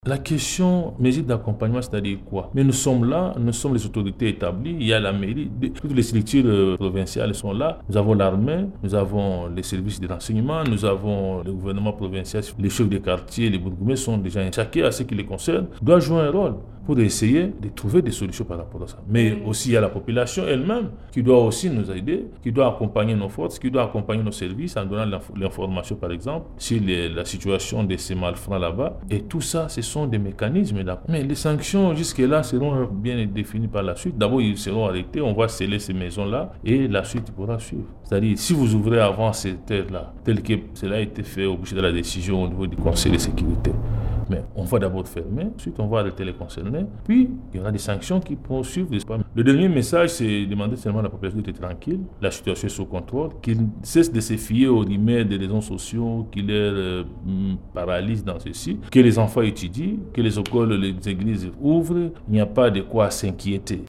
Cette précision a été prise à l’issue du conseil de sécurité tenu lundi passé renseigne le vice-gouverneur et gouverneur par intérim Dunia MASUMBUKO BWENGE lors d’une interview accordée au reporter de votre media dimanche 6 avril 2025.
Dunia MASUMBO BWENGE , vice-gouverneur et gouverneur par intérim